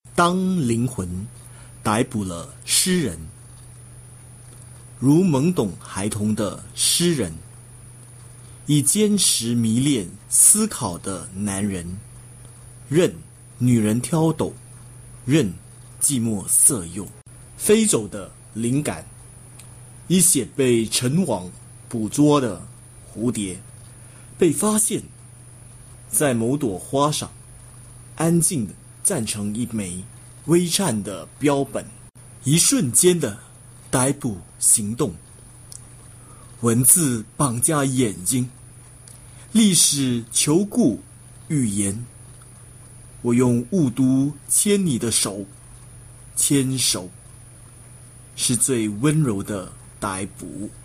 poem in mandarin